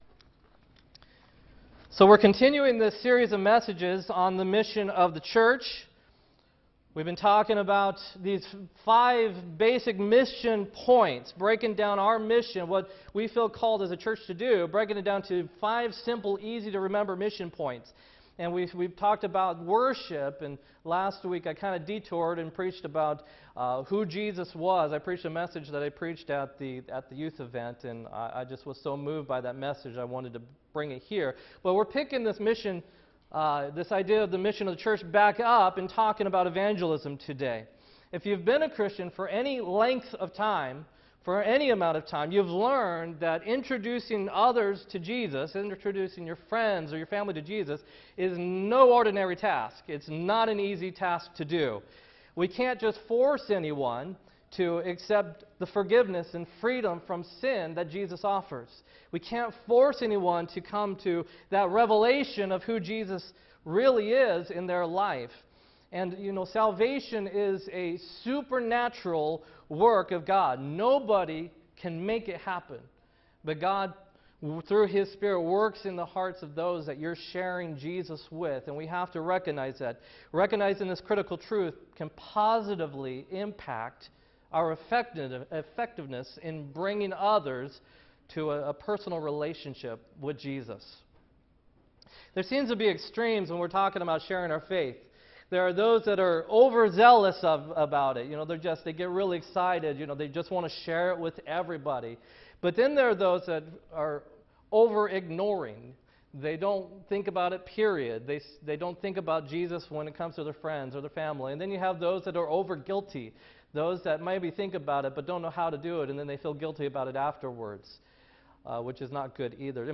8-13-16 sermon